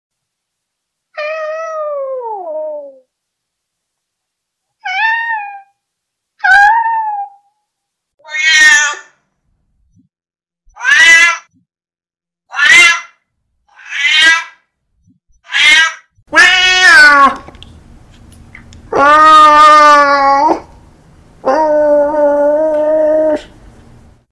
Gatto Che Piange Effetto Sound Effects Free Download